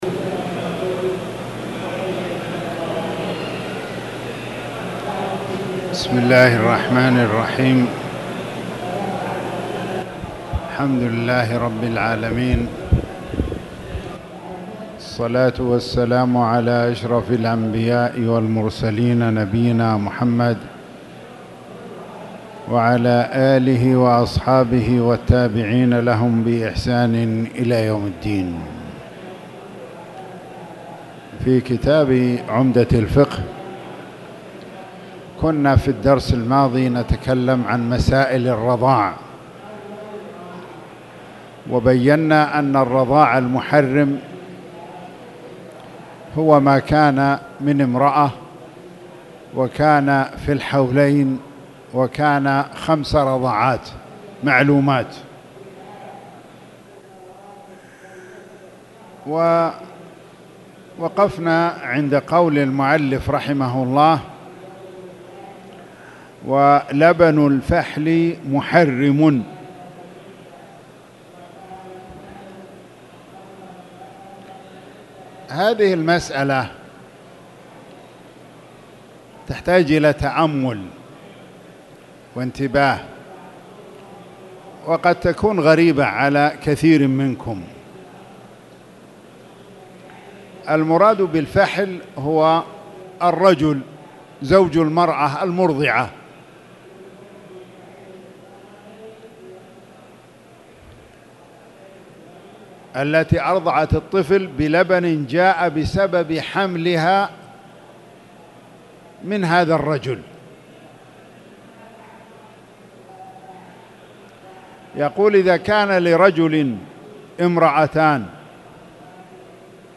تاريخ النشر ٢٥ ربيع الأول ١٤٣٨ هـ المكان: المسجد الحرام الشيخ